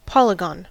Ääntäminen
IPA : /ˈpɒlɪɡən/